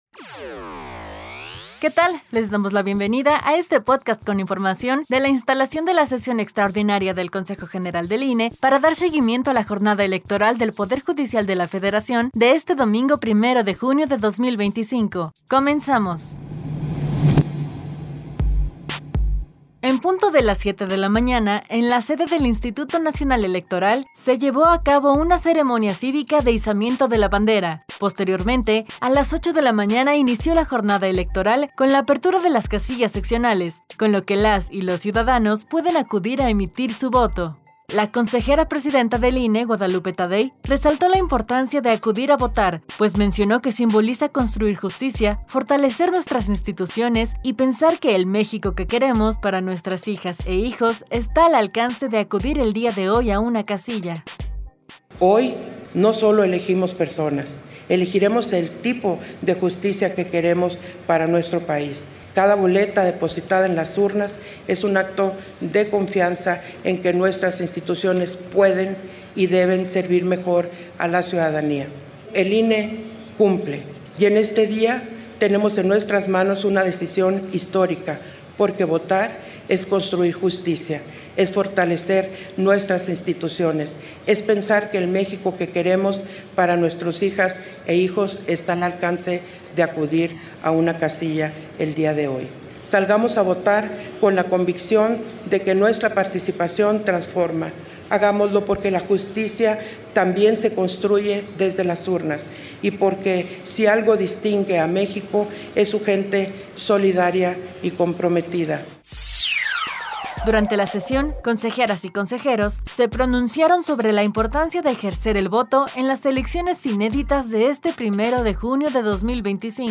Audio sobre la instalación de la Sesión extraordinaria del Consejo General del INE para dar seguimiento a la Jornada electoral del 1 de junio de 2025